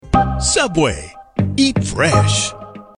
美式英语中年激情激昂 、大气浑厚磁性 、沉稳 、娓娓道来 、神秘性感 、调性走心 、素人 、男专题片 、宣传片 、纪录片 、广告 、80元/百单词男英4 美式英语 广告汇总 宜家家居星巴克士力架 激情激昂|大气浑厚磁性|沉稳|娓娓道来|神秘性感|调性走心|素人